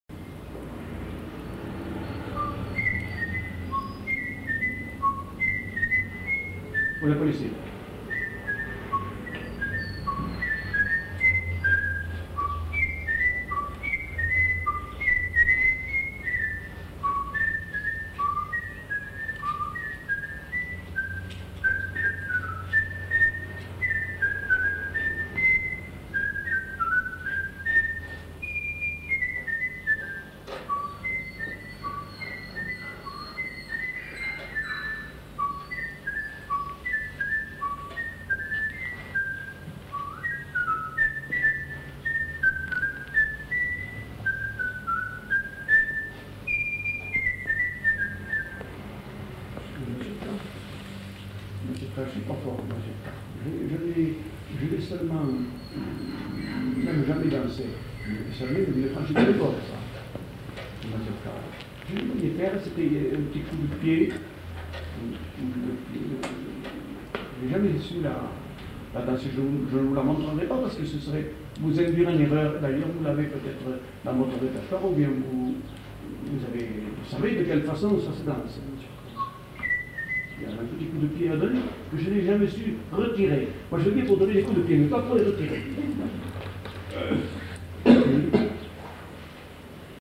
Varsovienne (sifflé)
Aire culturelle : Agenais
Lieu : Foulayronnes
Genre : chant
Effectif : 1
Type de voix : voix d'homme
Production du son : sifflé
Danse : varsovienne